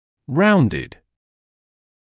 [‘raundɪd][‘раундид]округлый, закруглённый